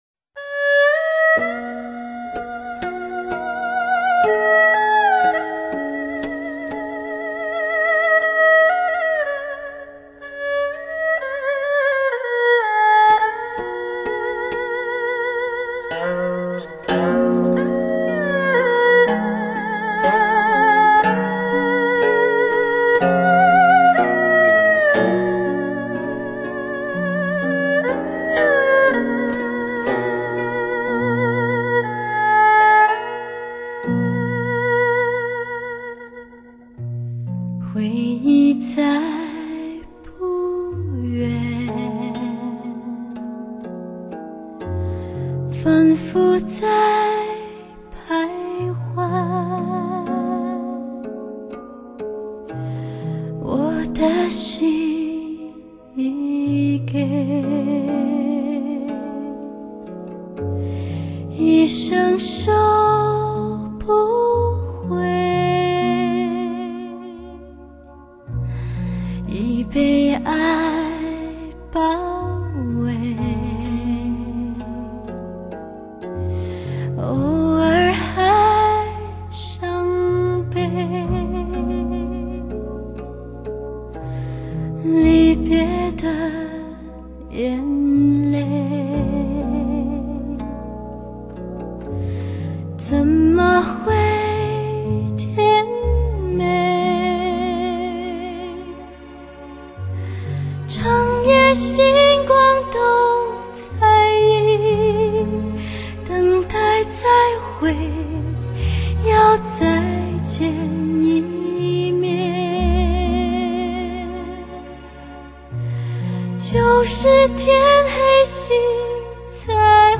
类　别：DSD
碟内人声甜美圆润，乐器质感玲珑通透，层次感清晰可见，音效超卓，沥沥在耳，是唱者与聆听的心灵交流上佳之作。